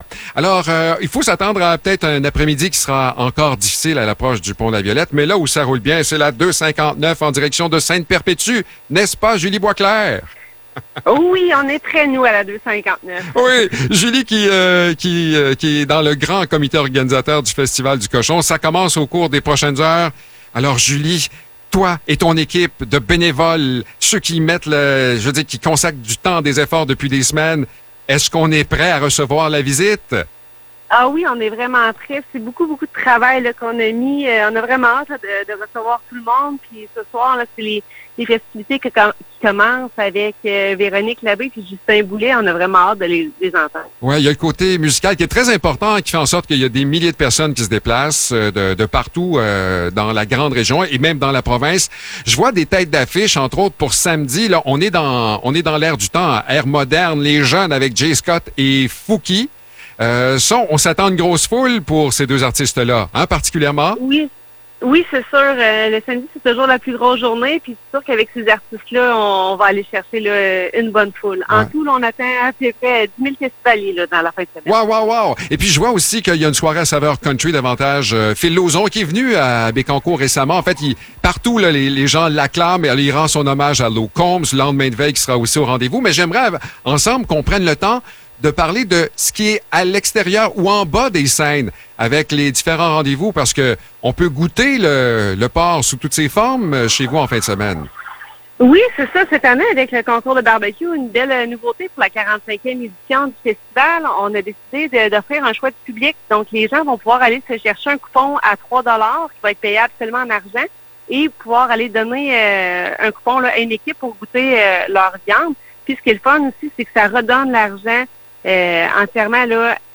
Entrevue pour le Festival du Cochon de Ste-Perpétue